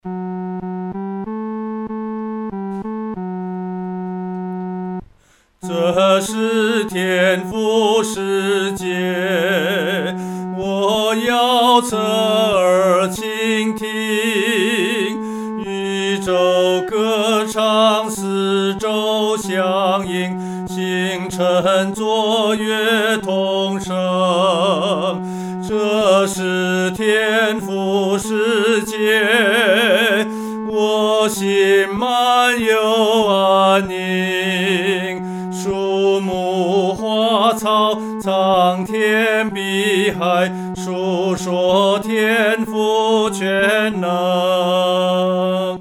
合唱
男高